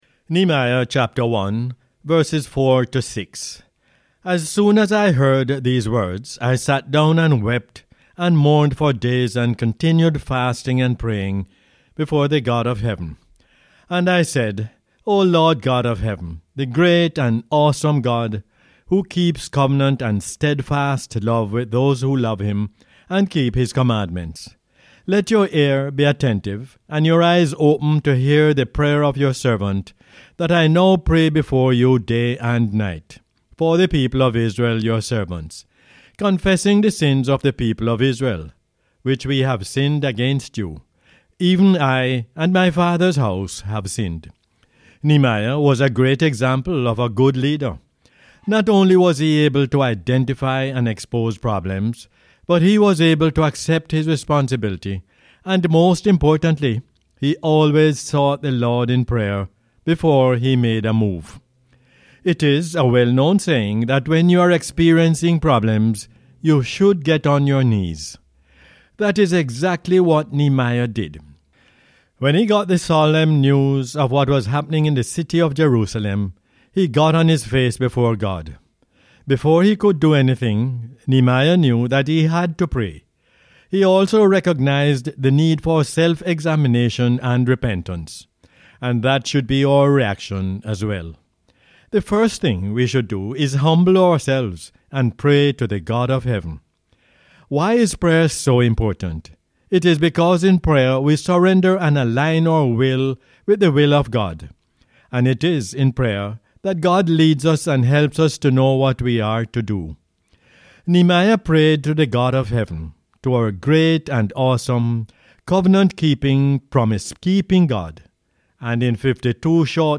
Nehemiah 1:4-6 is the "Word For Jamaica" as aired on the radio on 11 January 2019.